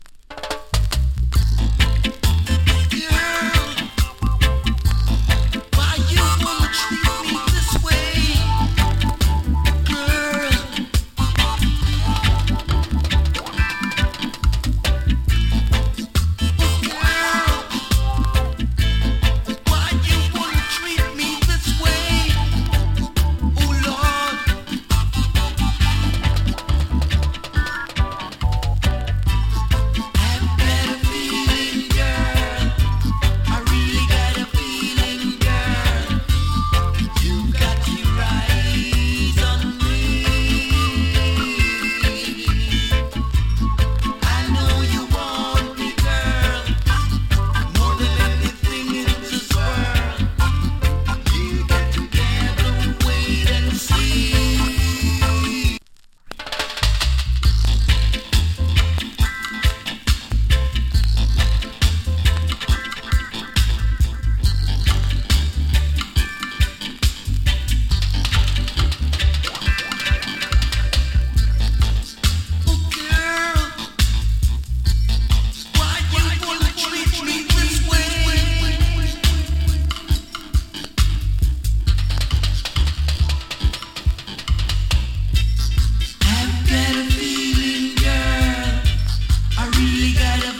チリ、パチノイズ少し有り。